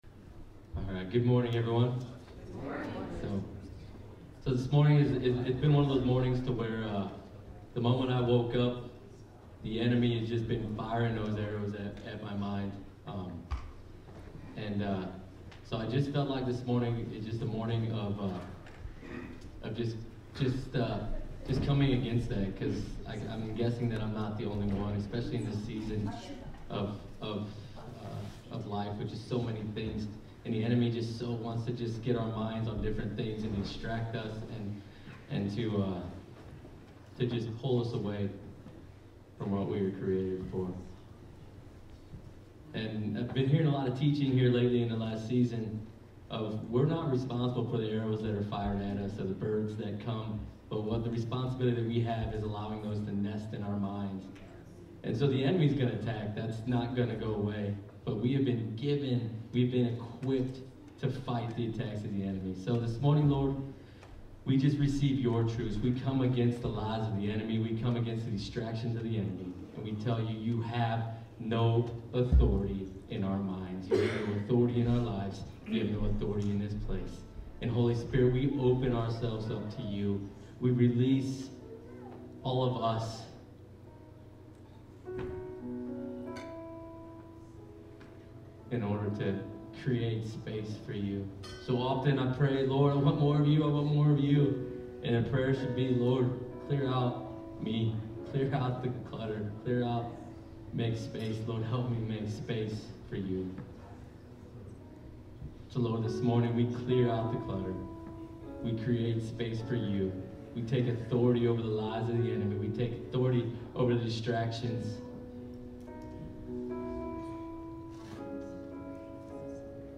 Sermons | Mission 72